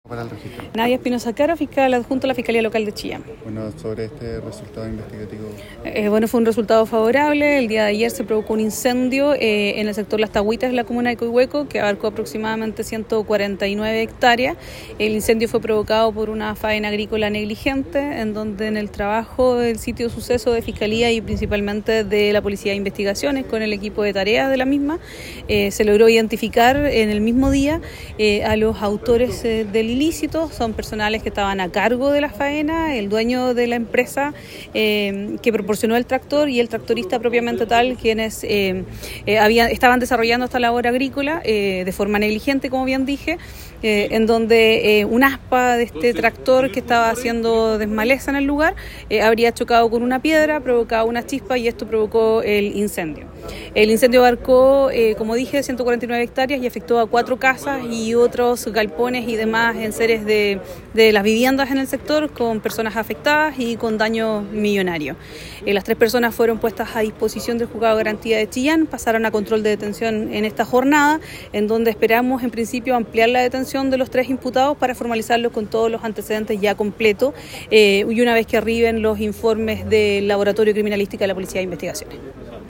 Fiscal Nadia Espinoza entrega información en punto de prensa respecto incendio ocurrido en sector Las Taguitas camino a Coihueco. El trabajo investigativo, coordinado con fuerza de tarea de la PDI y Conaf, permitió la identificación y detención de tres personas, que fueron a puestas a disposición del juzgado de garantía de Chillán, detencion que fue ampliada por 24 horas.